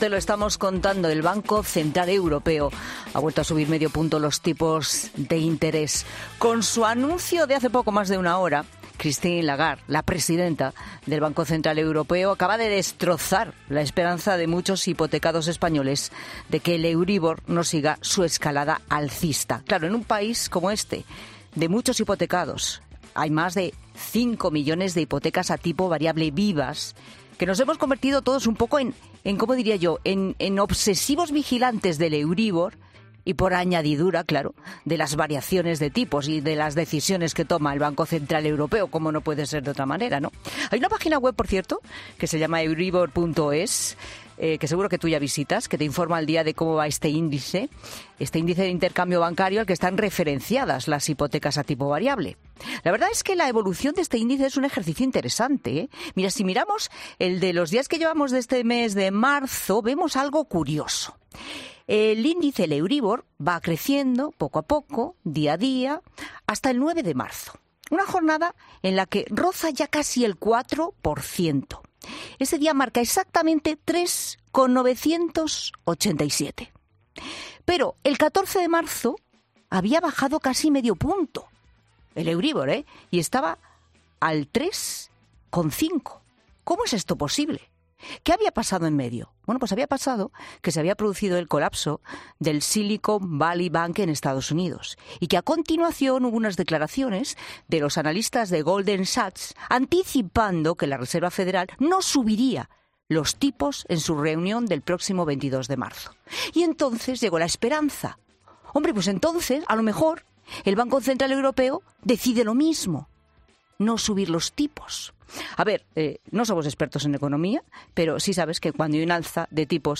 El economista analiza en La Tarde los motivos del BCE para subir los tipos de interés y que se está poniendo en riesgo la capacidad de recuperación...